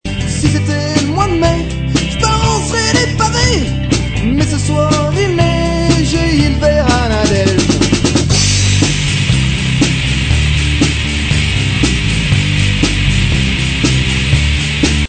des musiques auto-produites
punk-rock